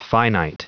Prononciation du mot finite en anglais (fichier audio)
Prononciation du mot : finite
finite.wav